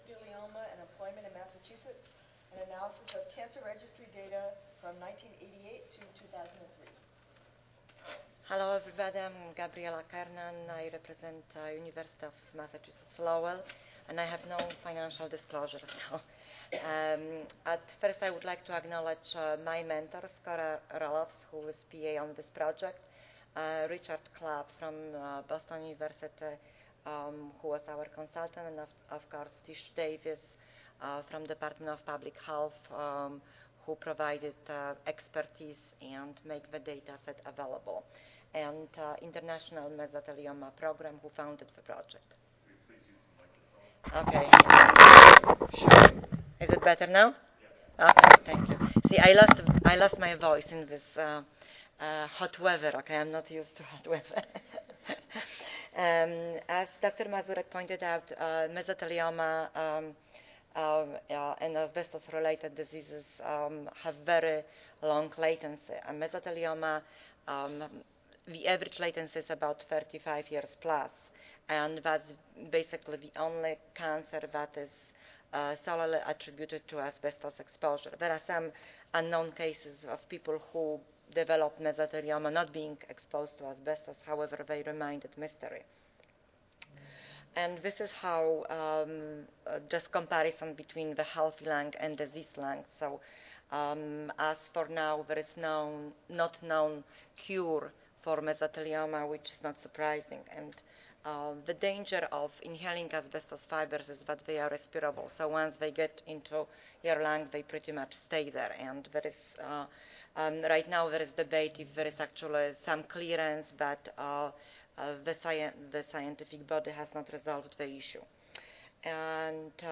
Oral This session will discuss submitted reports on field work related to Occupational epidemiology, surveillance and screening